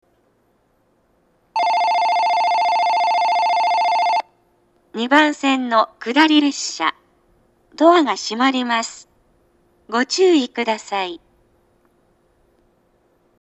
発車ベル（高音）
発車の際には高音のベルが流れます。
ダイヤが厳しいので、発車ベルは長めには鳴りにくいです。